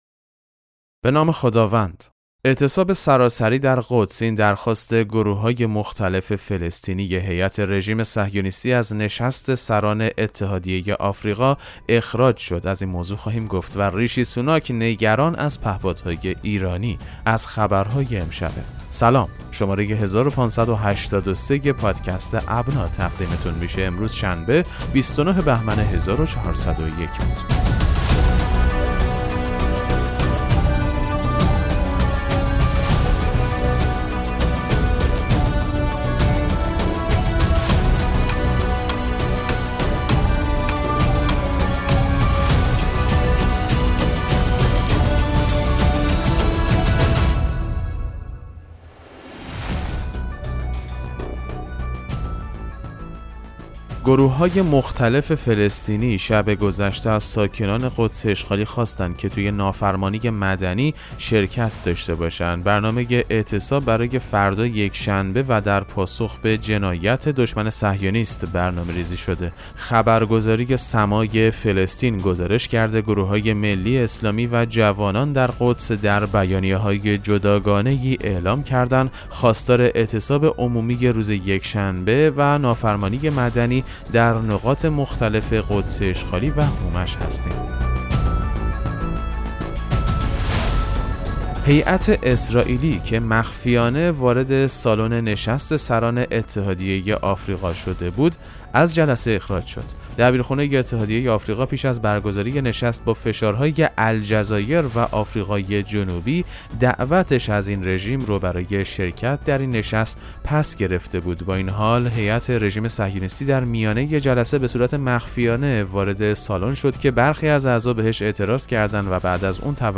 پادکست مهم‌ترین اخبار ابنا فارسی ــ 29 بهمن 1401